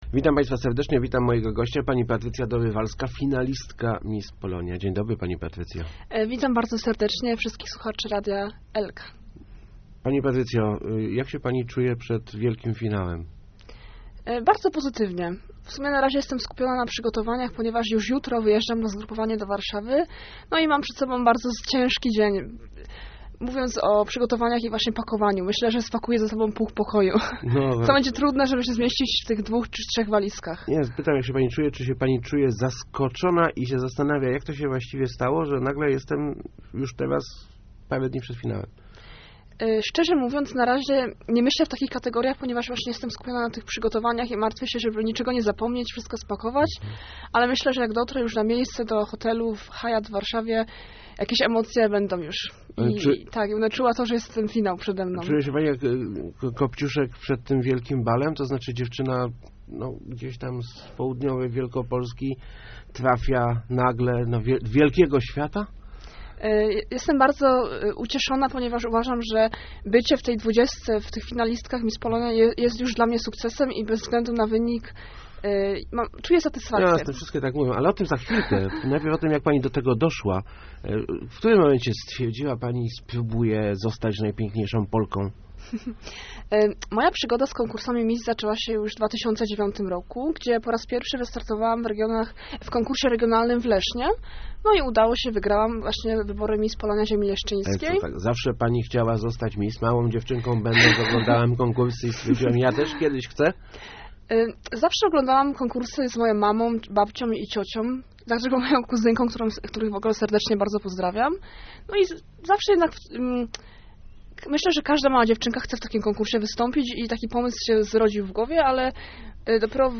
Taki ma męski głos trochę nie>?
Głos jakiś taki męski, hahaha